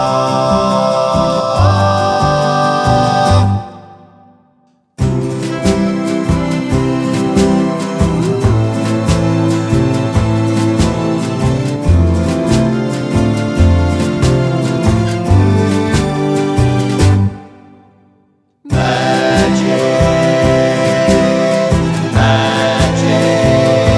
no Backing Vocals Soul / Motown 2:24 Buy £1.50